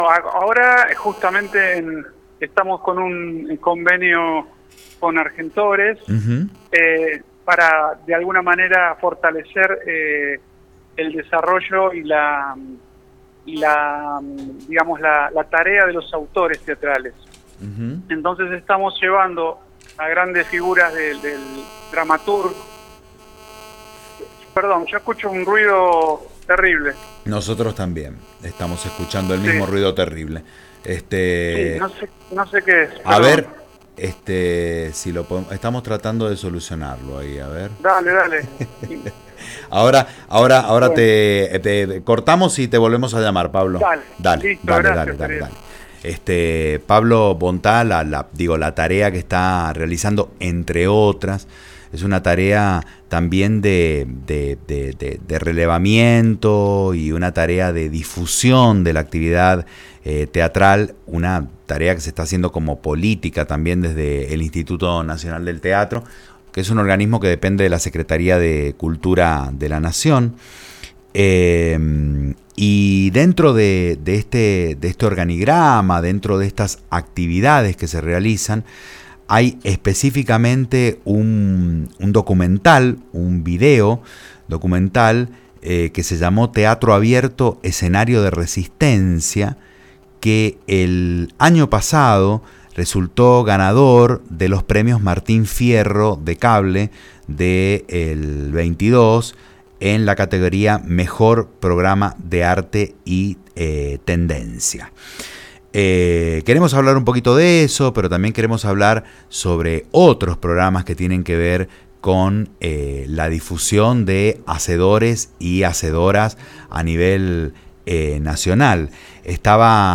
En el marco de la Teatrina 2023, Condado Radio emite entrevistas especiales con artistas de la escena local y nacional.